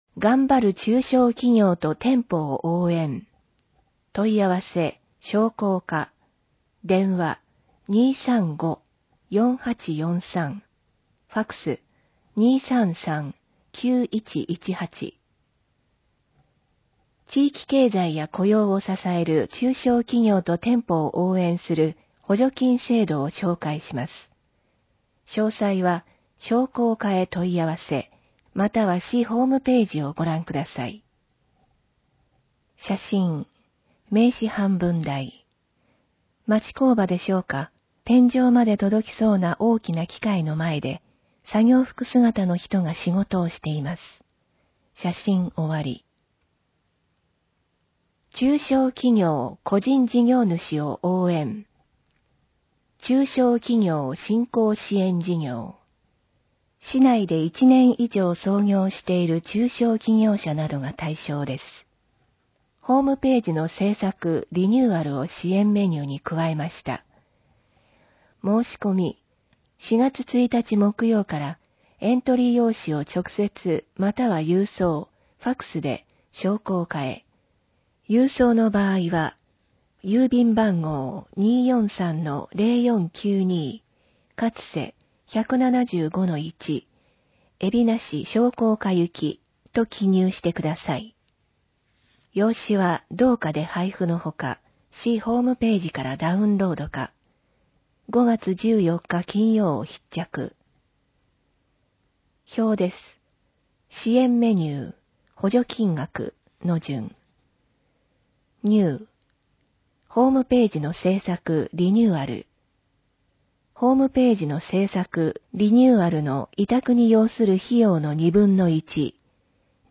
広報えびな 令和3年4月1日号（電子ブック） （外部リンク） PDF・音声版 ※音声版は、音声訳ボランティア「矢ぐるまの会」の協力により、同会が視覚障がい者の方のために作成したものを登載しています。